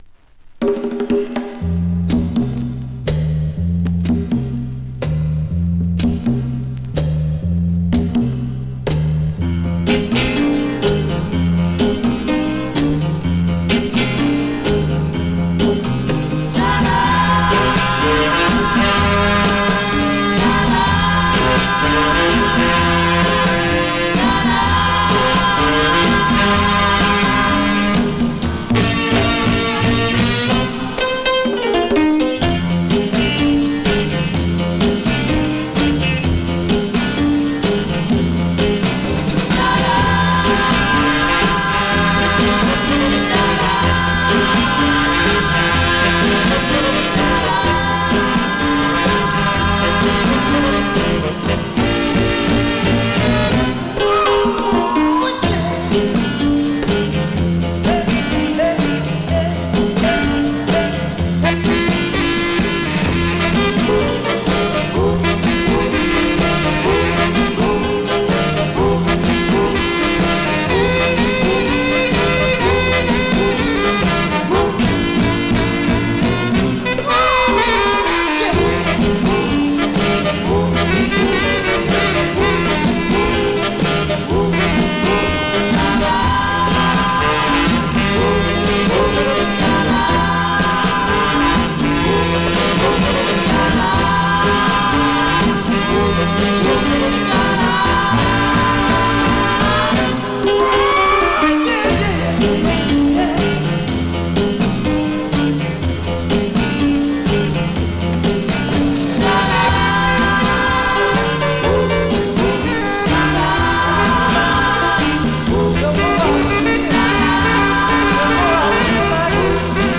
Vinyl has a few light marks plays great .
Great classic mid-tempo Rnb / Mod Instro dancer .